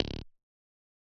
denied.ogg